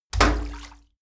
Royalty free sounds: Metal